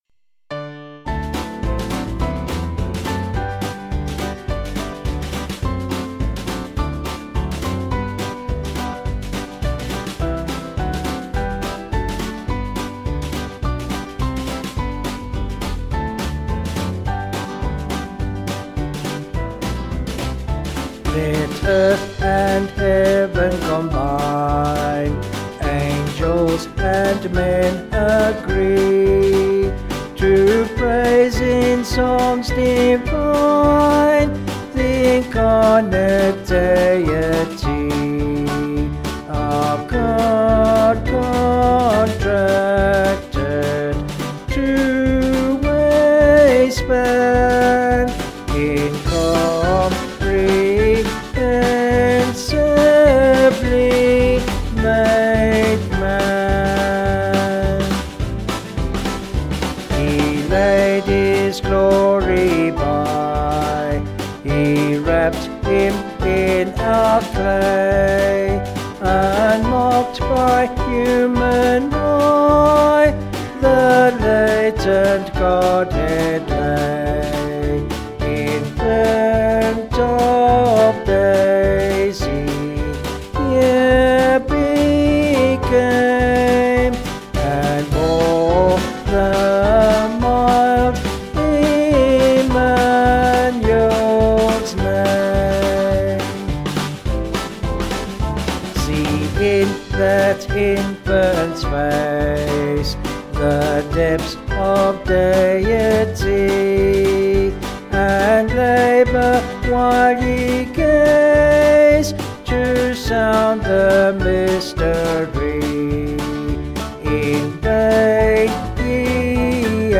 Vocal/Choral
Vocals and Band   264.6kb Sung Lyrics